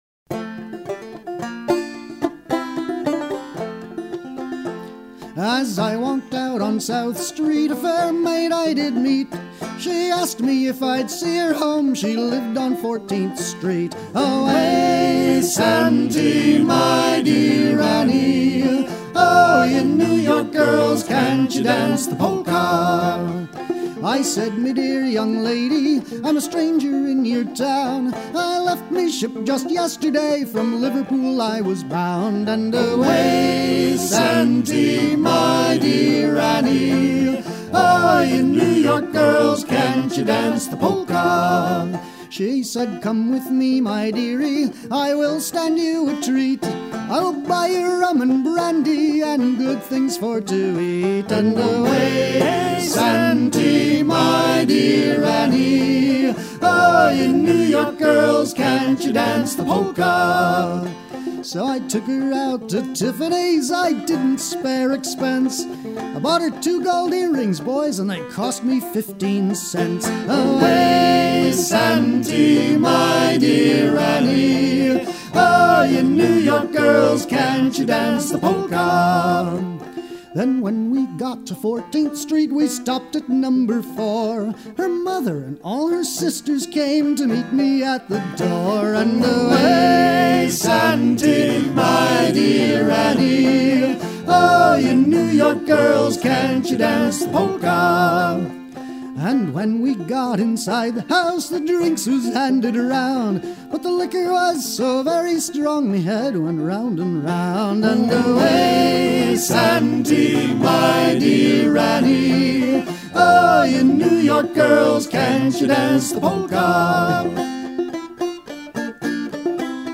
gestuel : à virer au cabestan
circonstance : maritimes
Pièce musicale éditée